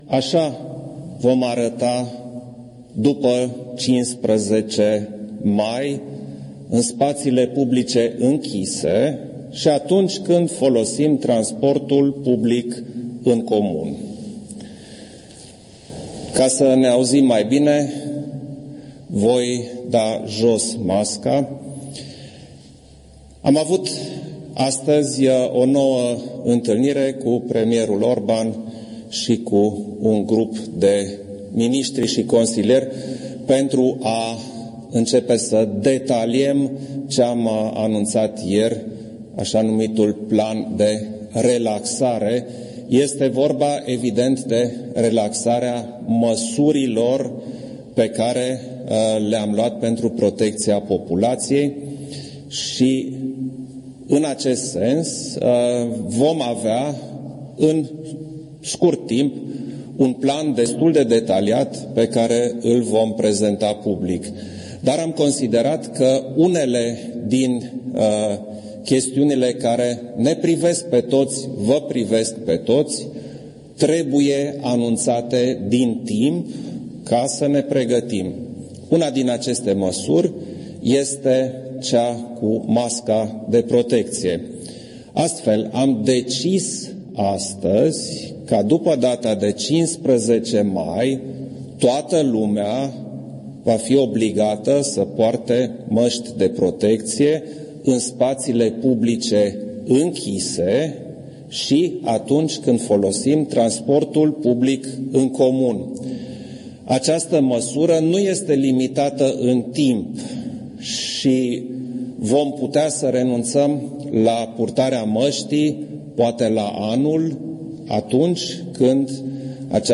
Declarațiile președintelui: